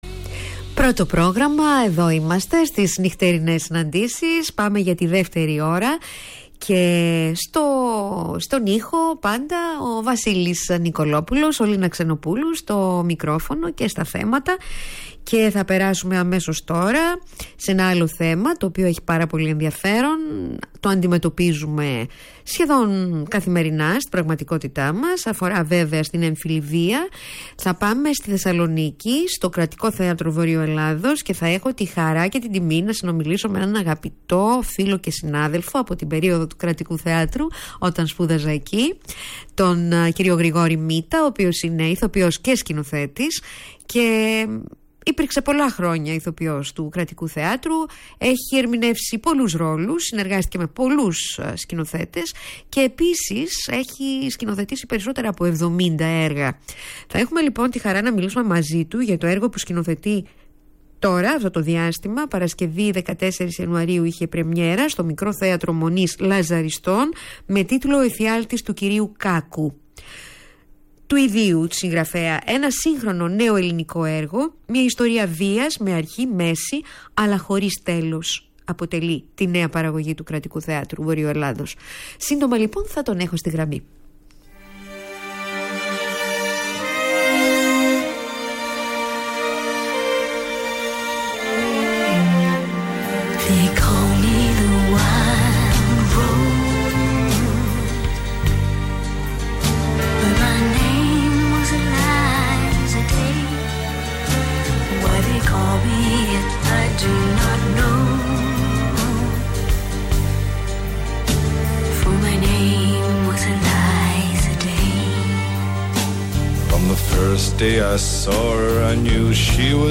Ακούστε την συνέντευξη - Θέλω να μας συστήσετε τον «Εφιάλτη του κυρίου Κάκου» Είναι ένα πραγματικά, σύγχρονο έργο.